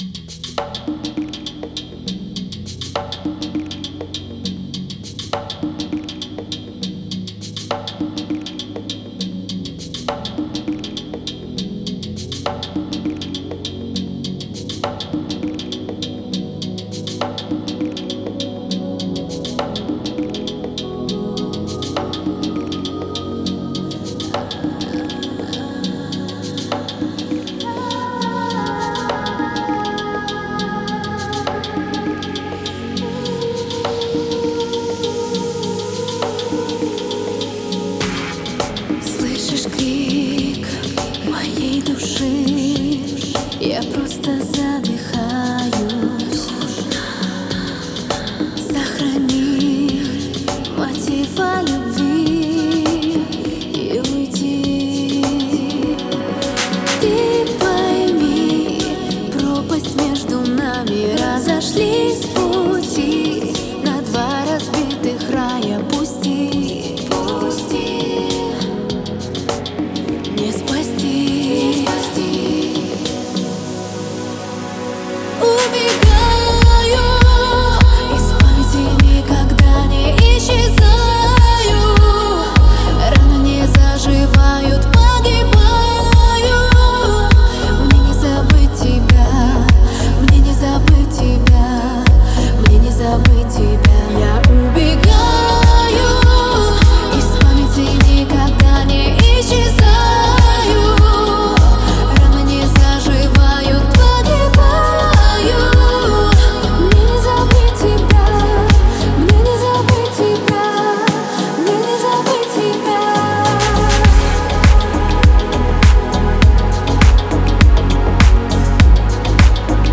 это зажигательный трек в жанре электронного dance